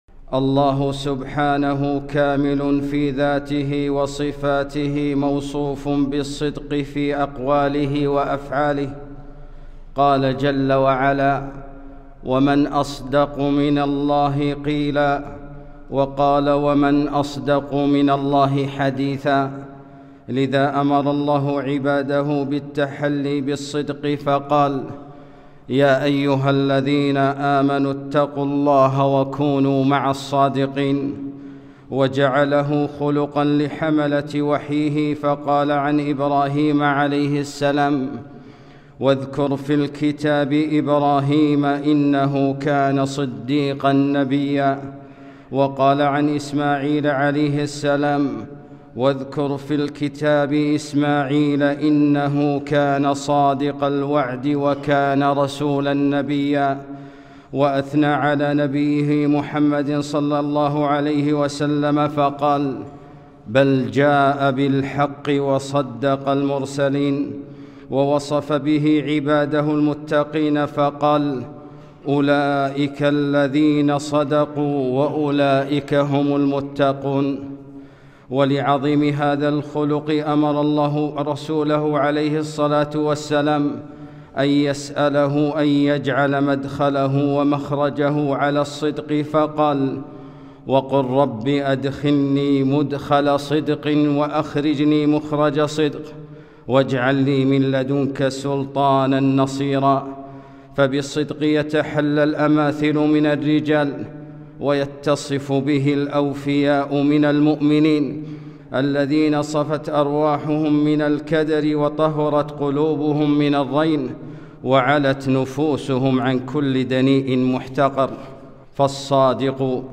خطبة - وكونوا مع الصادقين